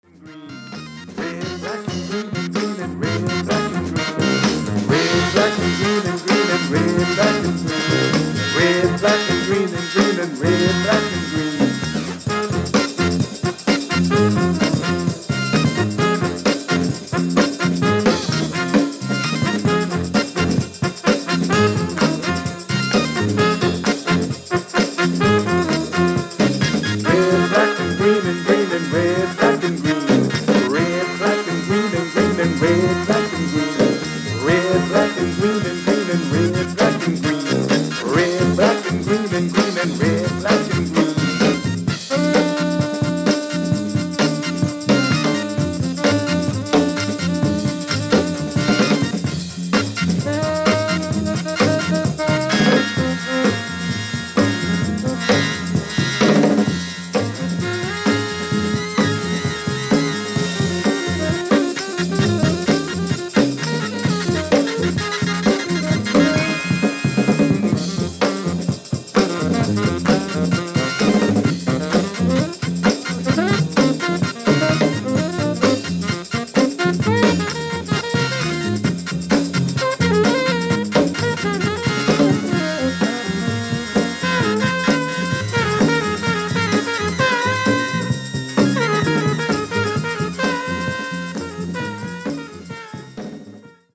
トランぺッター